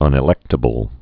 (ŭnĭ-lĕktə-bəl)